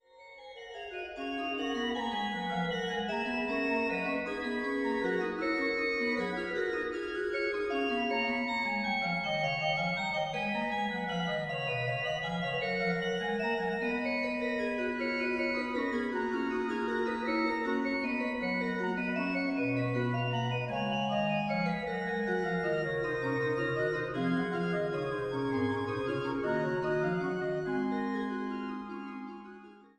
an der größten historischen Orgel des Rheinlandes